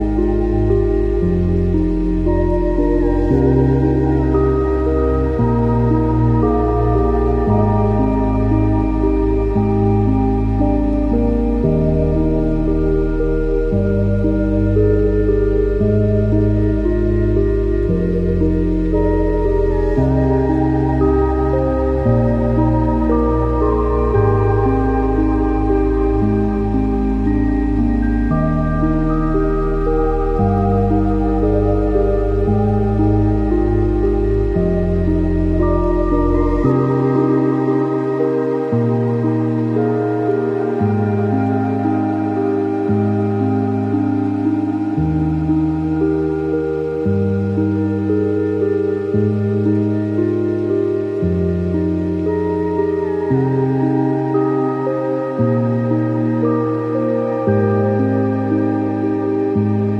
Kitten toast spread asmr…this time